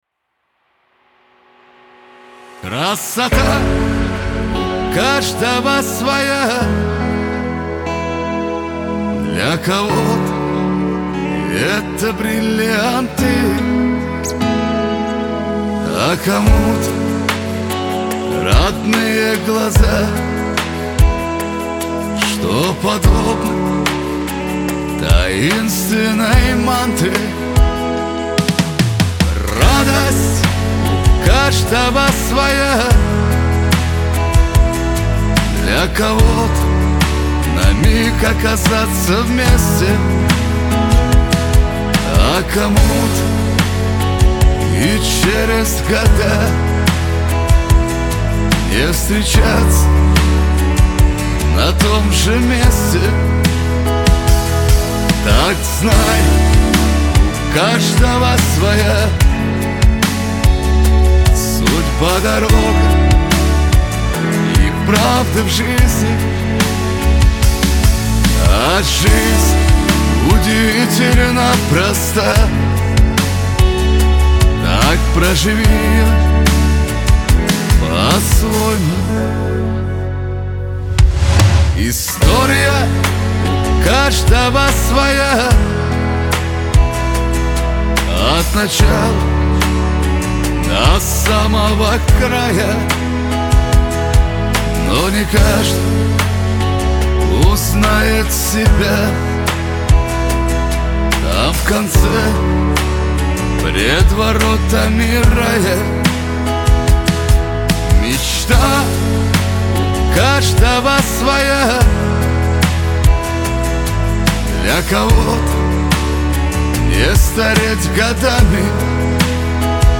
Трек размещён в разделе Русские песни / Шансон.